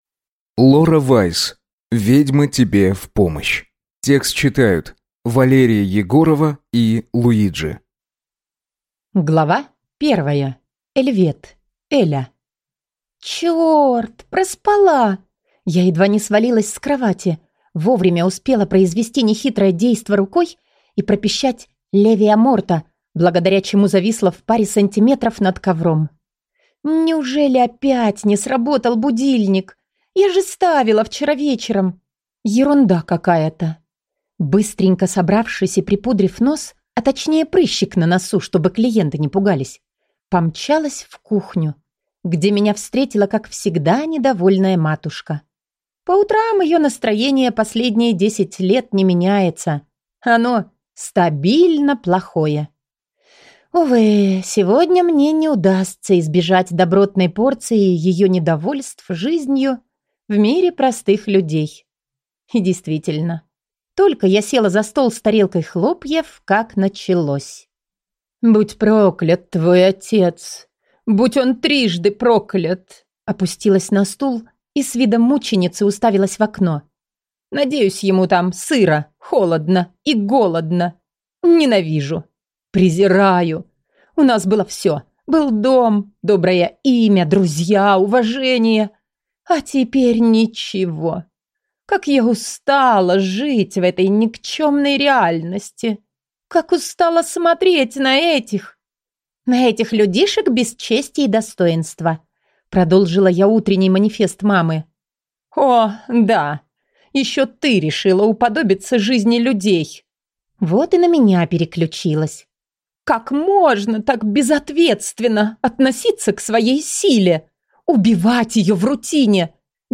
Аудиокнига Ведьма тебе в помощь | Библиотека аудиокниг